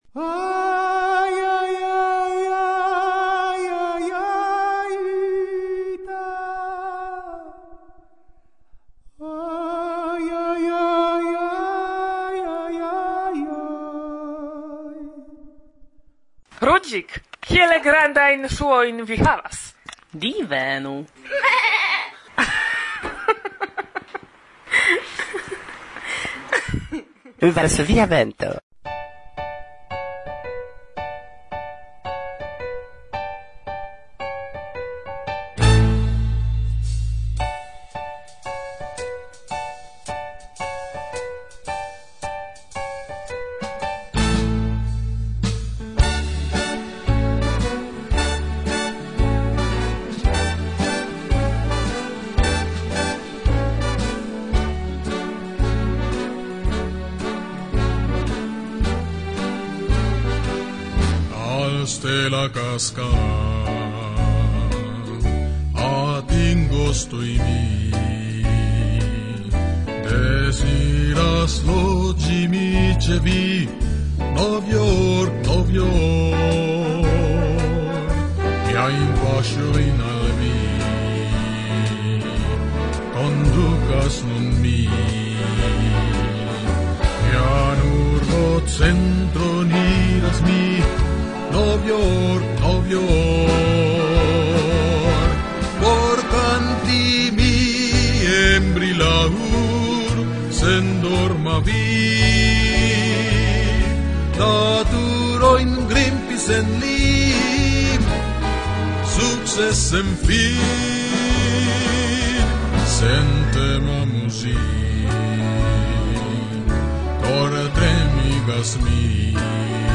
Priskribo de la elsendo La 46a elsendo – la 1a parto (tempo-daŭro 26:06): Iom pri la nova retejo de VVE. La dua interparolo kun la Perdita Generacio dum AS’2008.